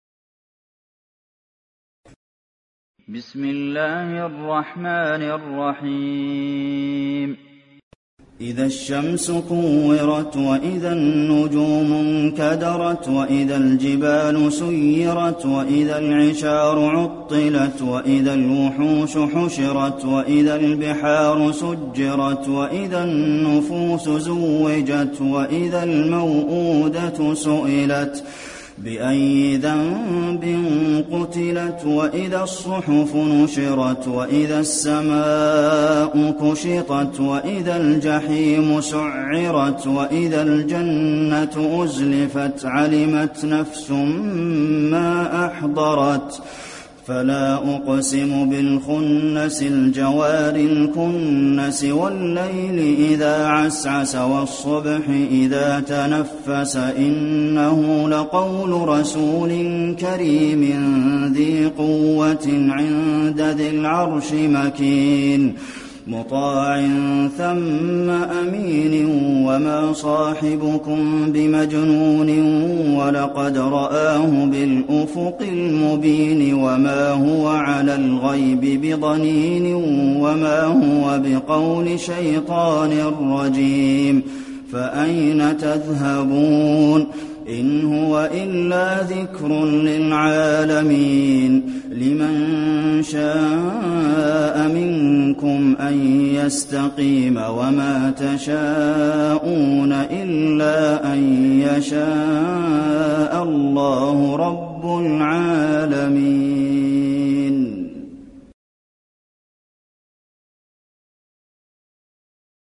المكان: المسجد النبوي التكوير